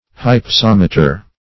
Hypsometer \Hyp*som"e*ter\, n. [Gr. ? height + -meter.]